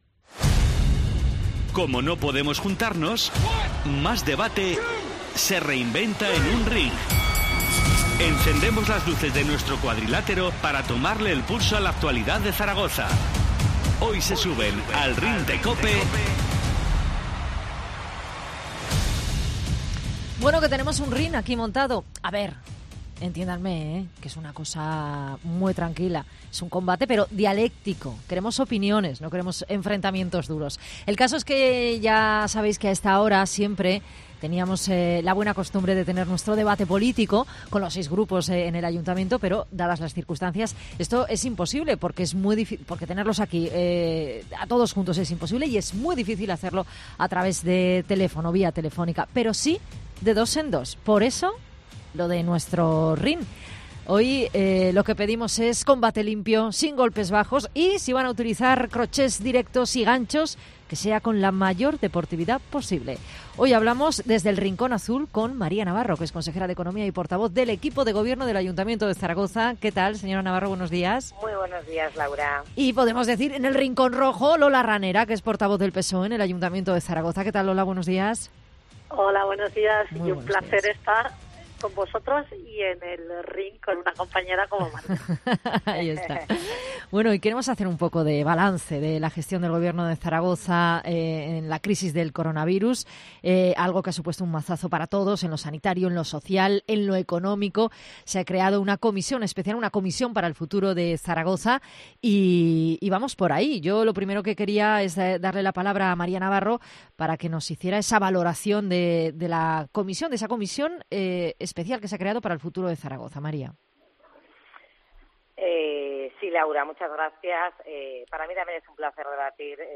Cada semana, representantes de los grupos parlamentarios pasarán, de dos en dos, en un combate cuerpo a cuerpo, por nuestros estudios, siempre por la vía telefónica. Esta semana ha comenzado con el ‘combate’ entre María Navarro, del Partido Popular, y Lola Ranera, del PSOE . El tema del debate ha sido la ‘Comisión para el Futuro’ del ayuntamiento de Zaragoza y la gestión que ha realizado de esta crisis el gobierno de la ciudad .